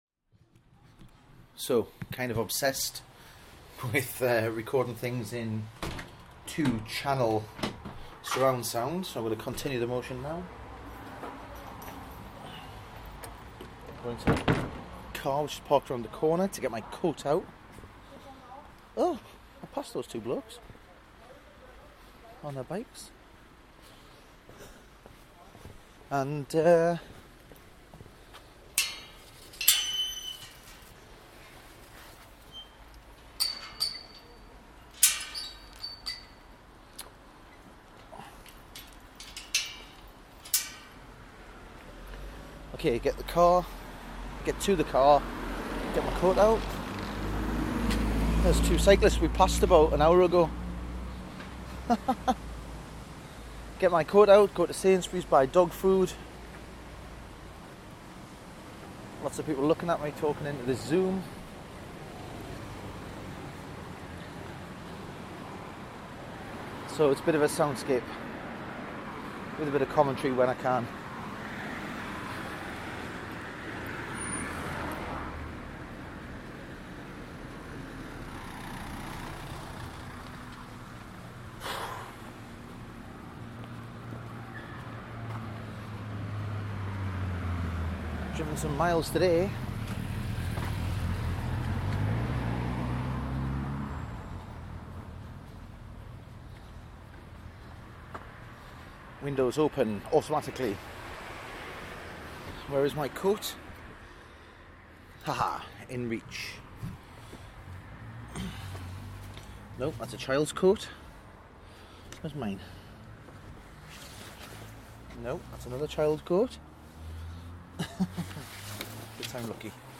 A walk into Ripon Centre [Soundscape with narration]